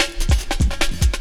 16LOOP01SD-R.wav